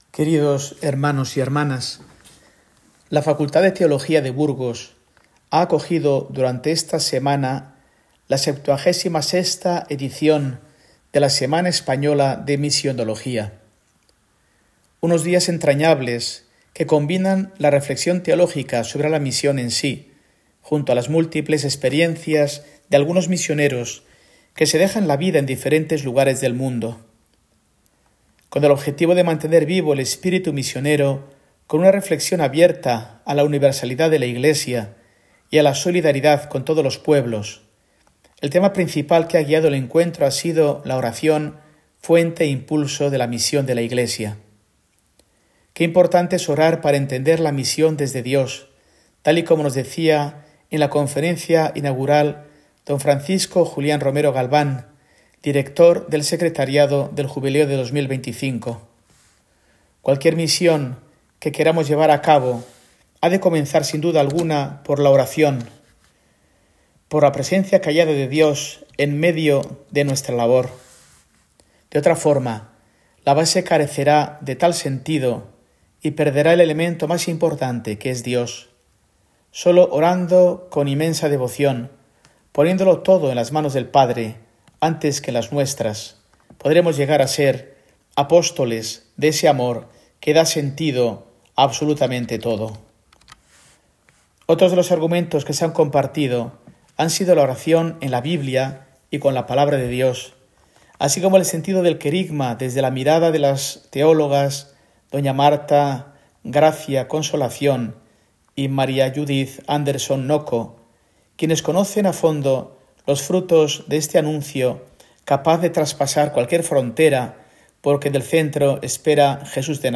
Mensaje semanal de Mons. Mario Iceta Gavicagogeascoa, arzobispo de Burgos, para el domingo, 7 de julio de 2024